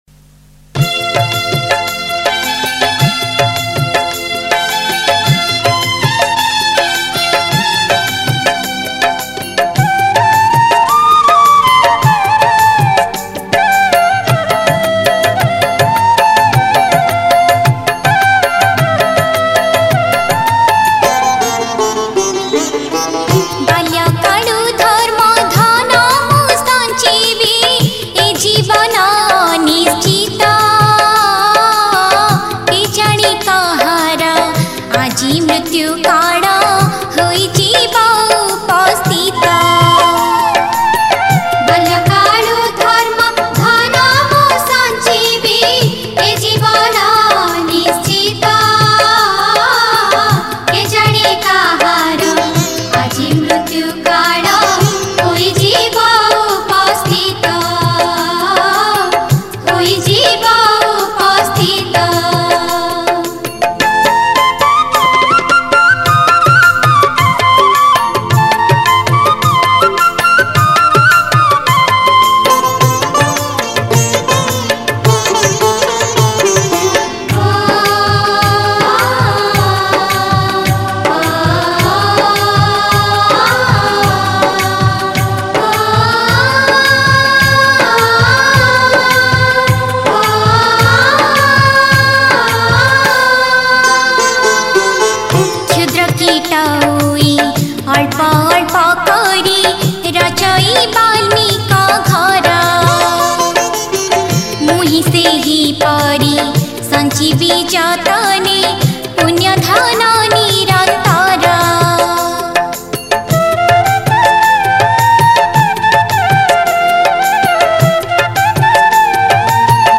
Category: Prathana